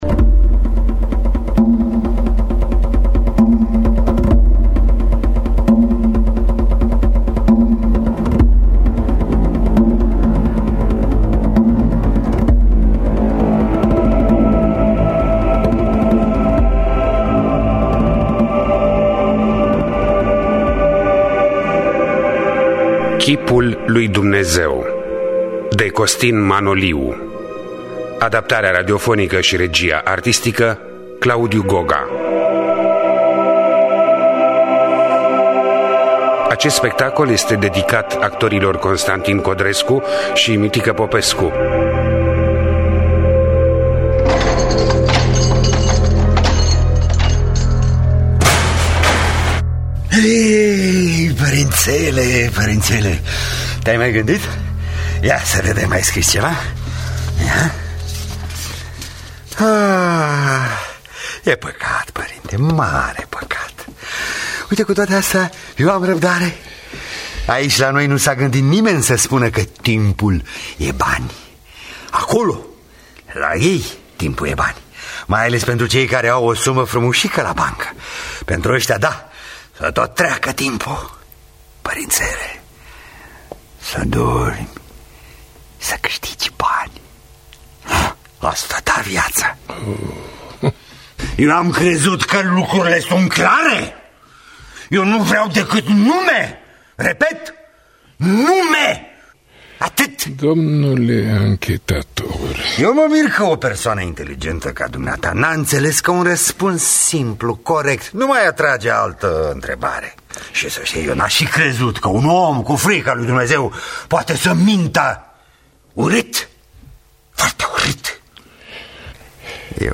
Chipul lui Dumnezeu de Ion-Costin Manoliu – Teatru Radiofonic Online